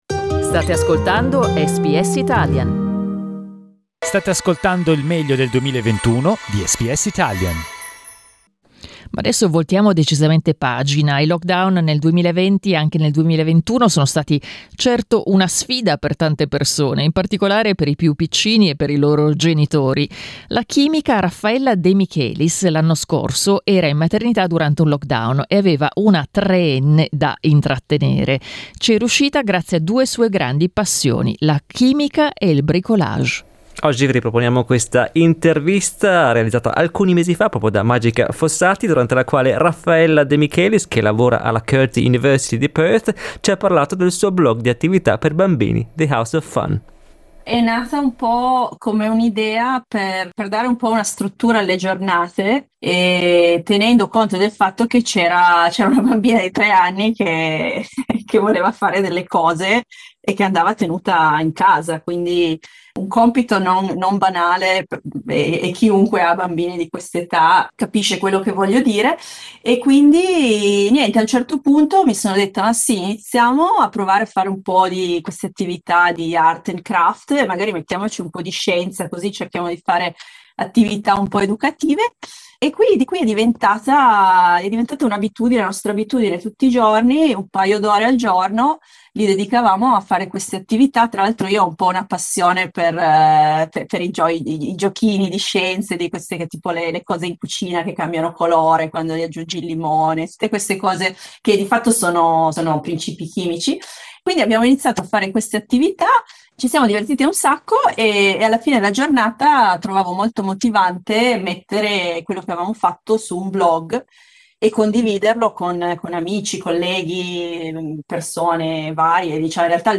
Ascolta il servizio: LISTEN TO Best of 2021: 'The House of Fun', un blog di attività da fare con i più piccini SBS Italian 11:24 Italian Le persone in Australia devono stare ad almeno 1,5 metri di distanza dagli altri.